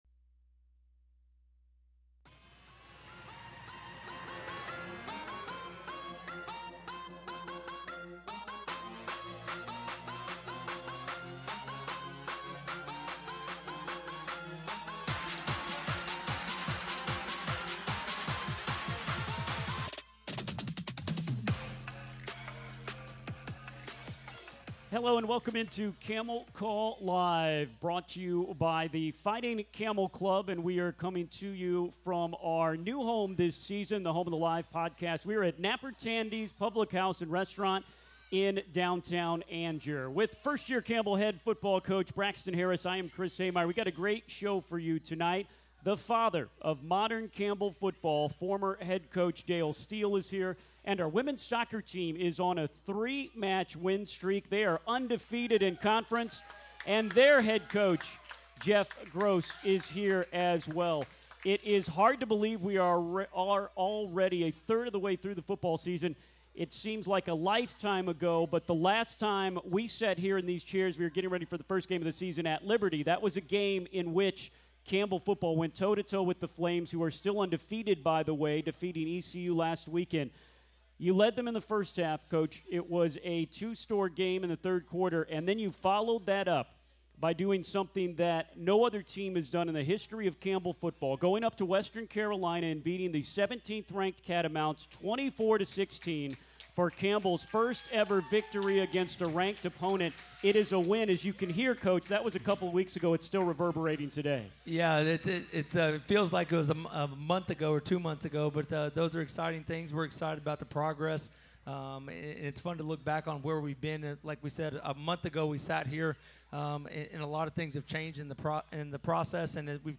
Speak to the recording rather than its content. It's Camel Call Live, recorded live from Napper Tandy's in Angier.